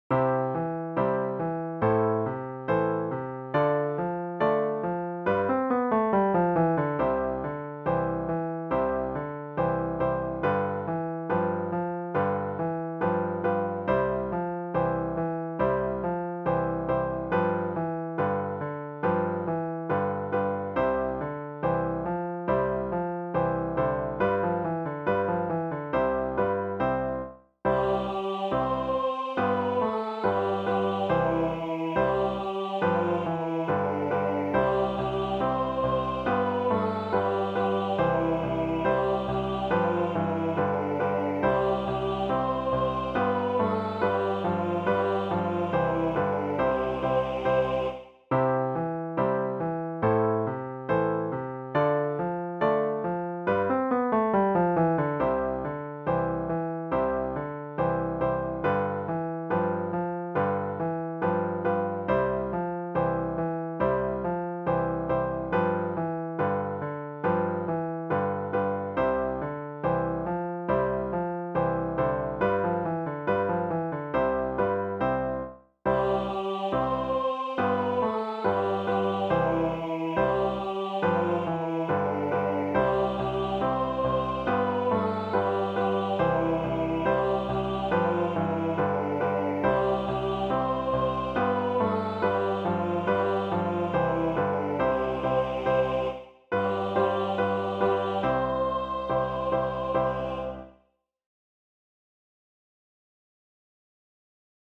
vi servirà  come base per cantare   o suonare